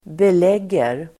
Uttal: [bel'eg:er]